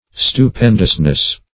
Stu*pen"dous*ness, n.